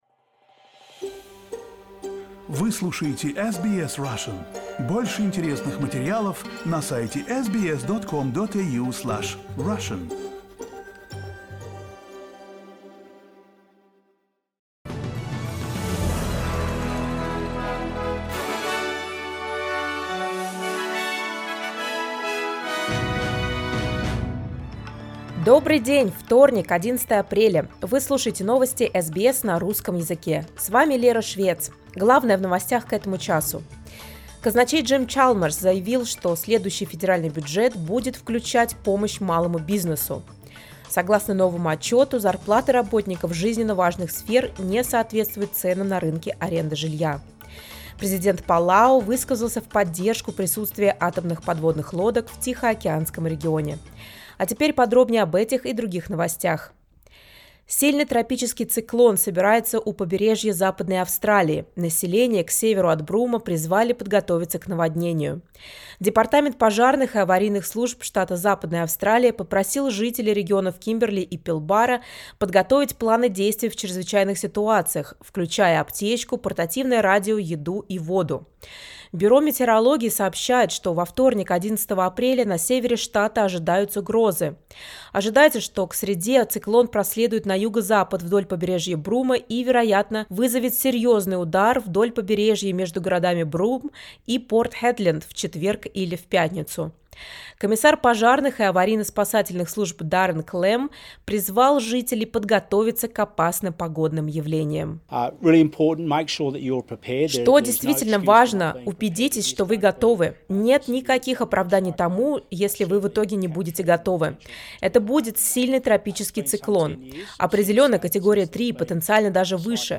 SBS news in Russian — 11.04.2023
Listen to the latest news headlines in Australia from SBS Russian.